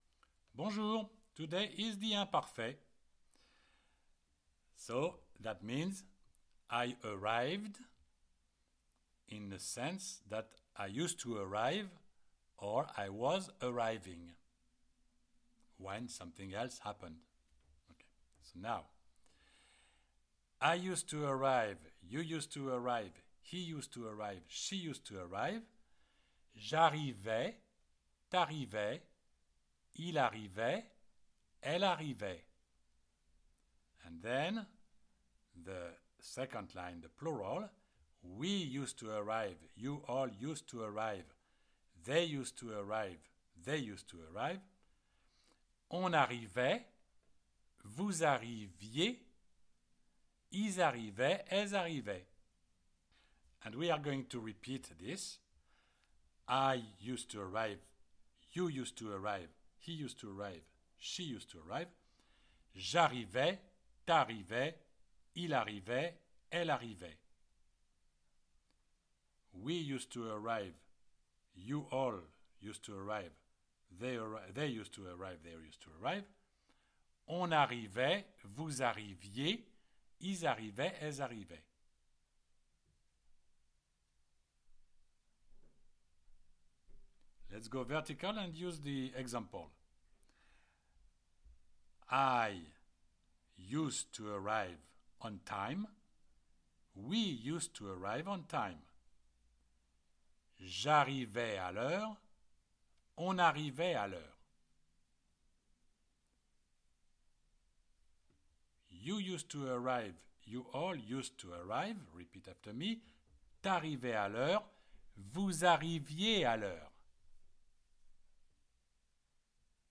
CLICK ON THE PLAY BUTTON BELOW TO PRACTICE ‘ARRIVER’, ‘TO ARRIVE’, IN THE IMPARFAIT TENSE Just the sight of the classic French Conjugation Chart makes it difficult to learn french download free .
You read and you repeat with the audio.